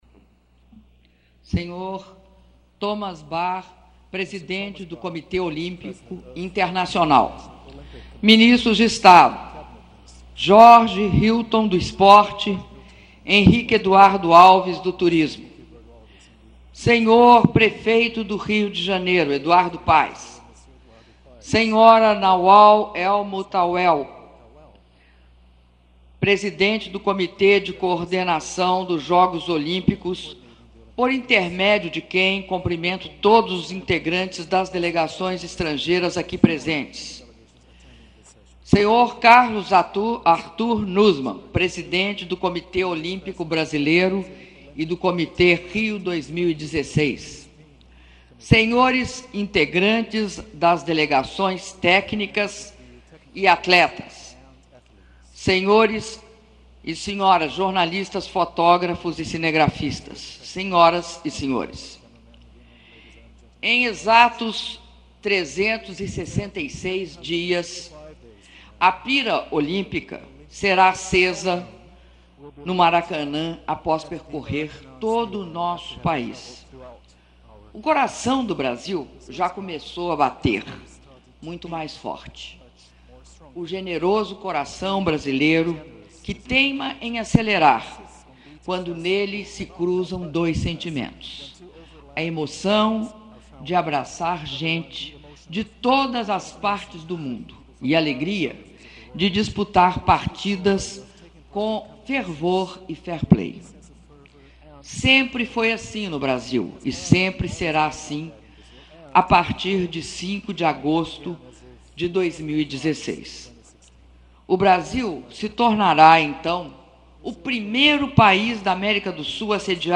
Áudio do discurso da presidenta da República, Dilma Rousseff, durante cerimônia de celebração de 1 ano para os Jogos Olímpicos Rio 2016 - Rio de Janeiro/RJ (05min25s)